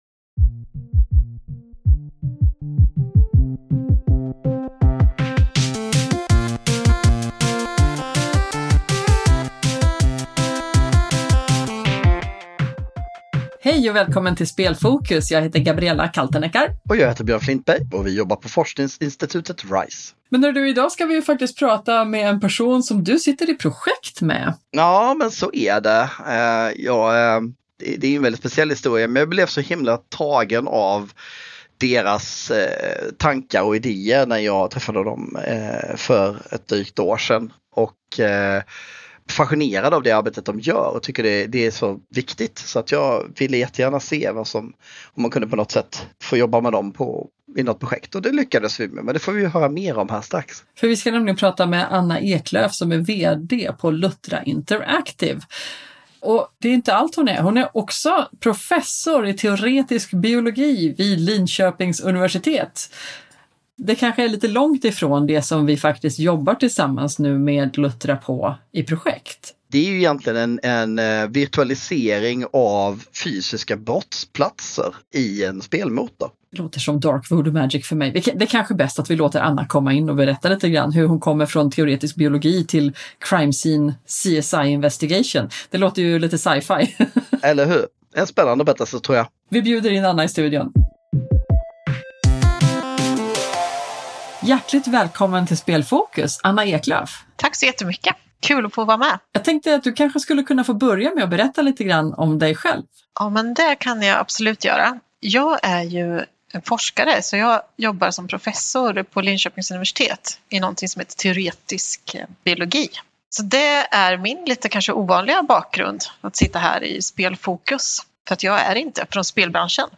Häng med på ett utforskande samtal i den fascinerande världen av serious games med Lutra Interactive, ett bolag som leder vägen i att bl.a. kombinera avancerade biologiska simuleringar med speldesignens dynamik.